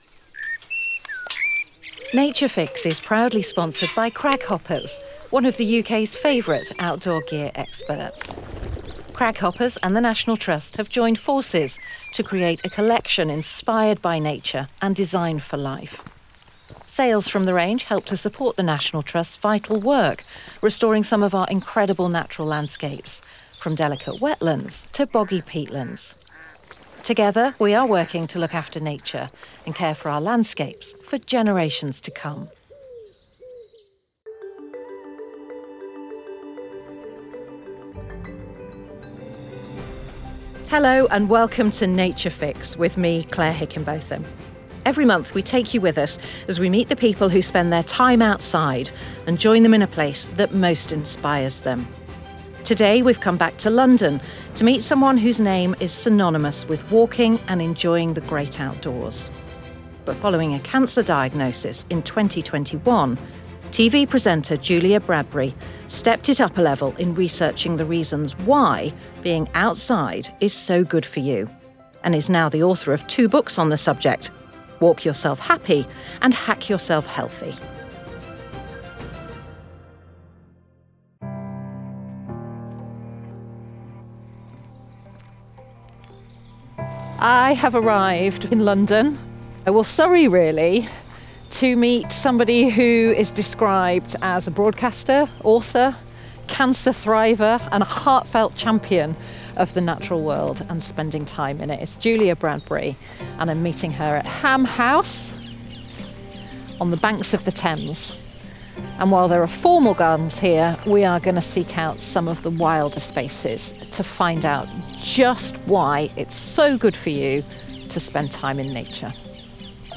Audio podcast from the National Trust with an interview with Julia Bradbury …